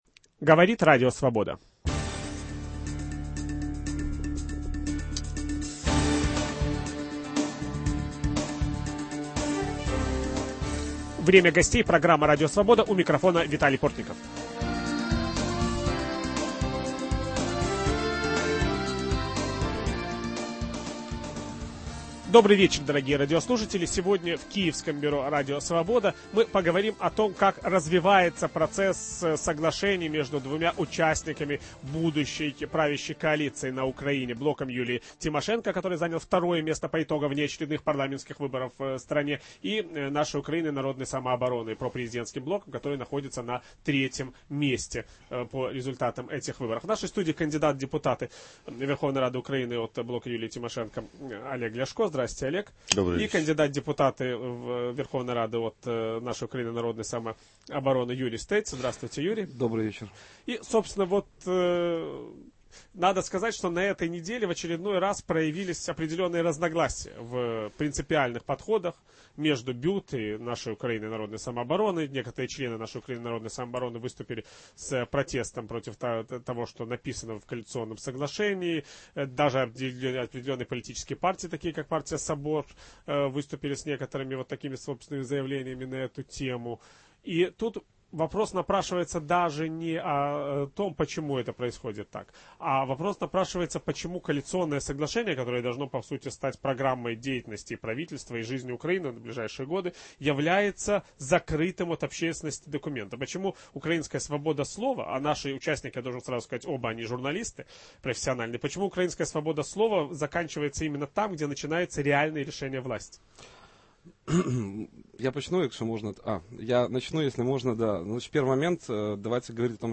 Почему возникли новые разногласия в "оранжевом лагере"? Об этом ведущий программы Виталий Портников беседует с кандидатом в депутаты Верховной Рады Украины от БЮТ Олегом Ляшко